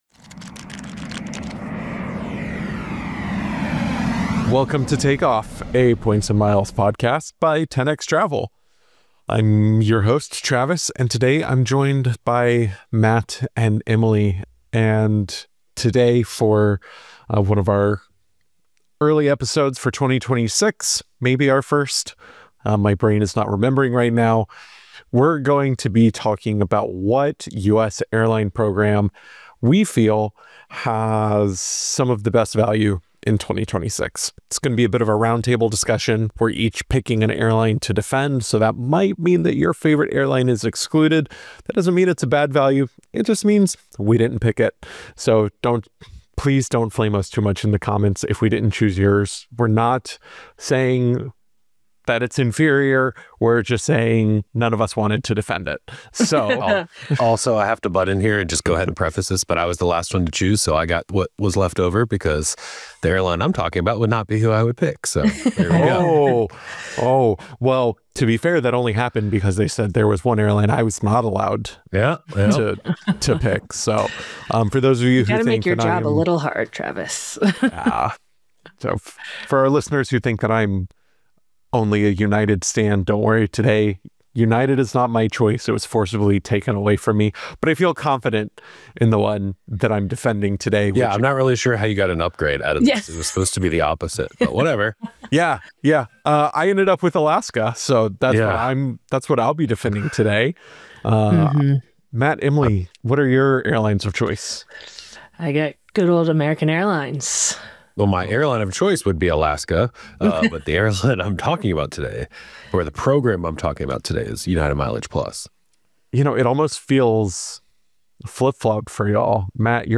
Which U.S. airline loyalty program delivers the most value in 2026? In this roundtable discussion, the 10xTravel crew debates Alaska Airlines, United MileagePlus, and American AAdvantage to determine which program stands out for earning miles, redeeming points, elite status perks, and partner networks.